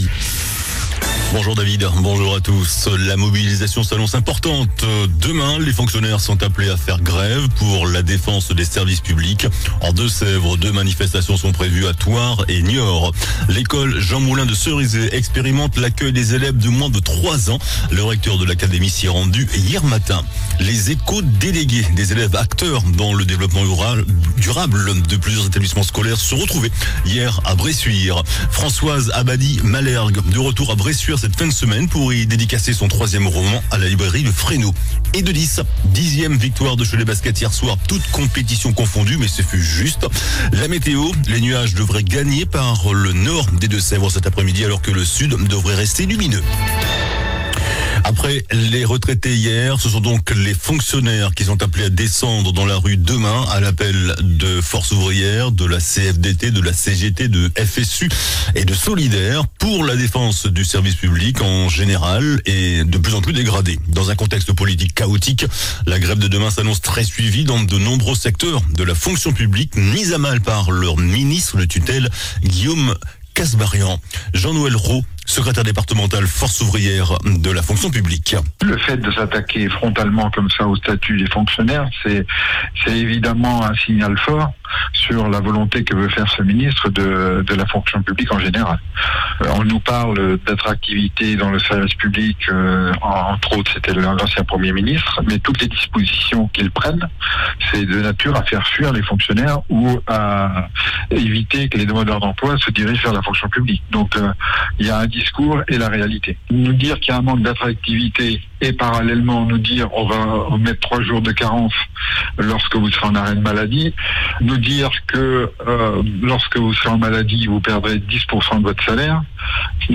JOURNAL DU MERCREDI 04 DECEMBRE ( MIDI )